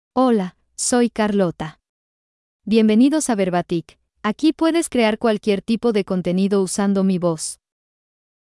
Carlota — Female Spanish (Mexico) AI Voice | TTS, Voice Cloning & Video | Verbatik AI
FemaleSpanish (Mexico)
Carlota — Female Spanish AI voice
Voice sample
Listen to Carlota's female Spanish voice.
Female
Carlota delivers clear pronunciation with authentic Mexico Spanish intonation, making your content sound professionally produced.